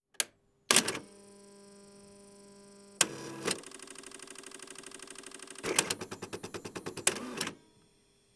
Encendido de una máquina de escribir electrónica
máquina de escribir
Sonidos: Oficina
Sonidos: Hogar